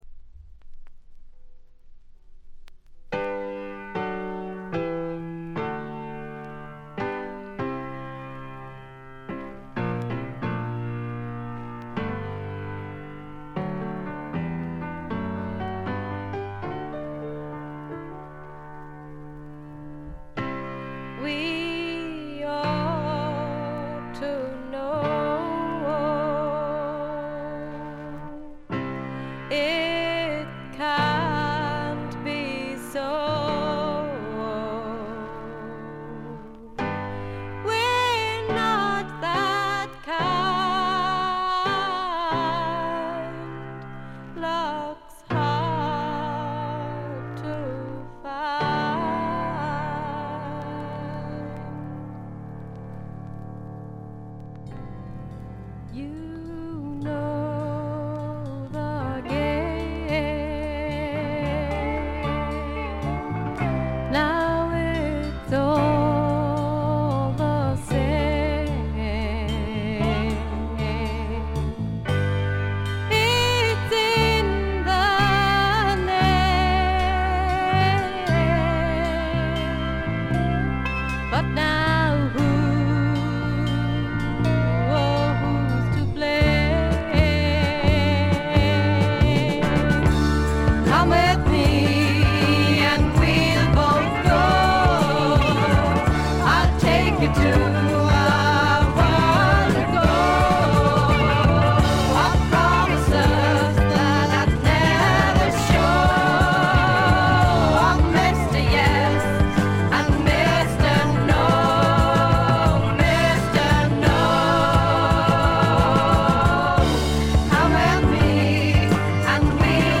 わずかなチリプチ程度。
試聴曲は現品からの取り込み音源です。
Recorded September 1971, Morgan Studio 2.